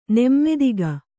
♪ nemmadiga